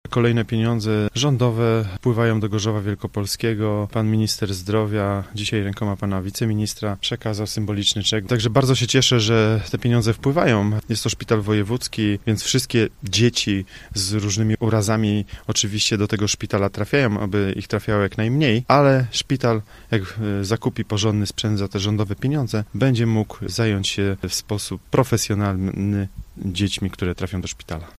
Uroczyste podpisanie umów miało dzisiaj miejsce w urzędzie wojewódzkim.
Pieniądze dla gorzowskiej lecznicy to szansa na szybszą pomoc najmłodszym pacjentom – powiedział obecny przy podpisaniu umów Sebastian Pieńkowski, przewodniczący gorzowskiej Rady Miasta z ramienia PiS.